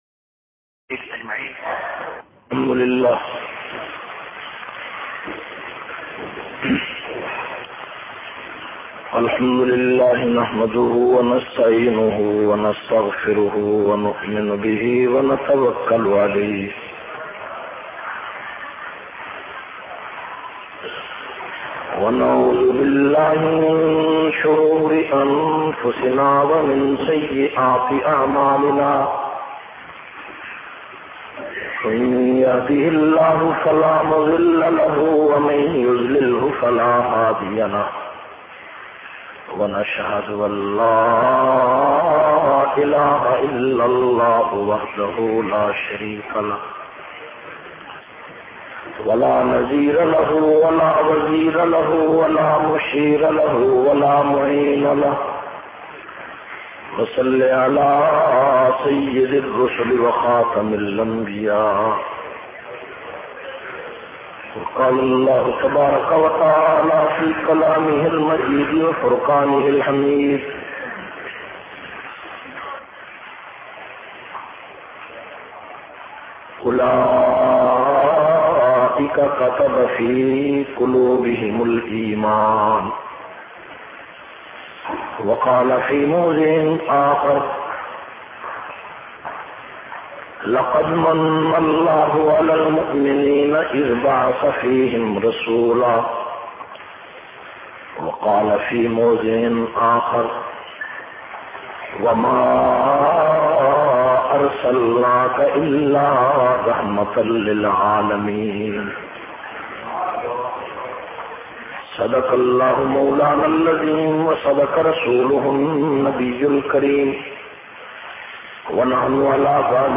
102- Nabi-saaw-ki-shaan-aur-rehmat-biyaan-in-leeds.mp3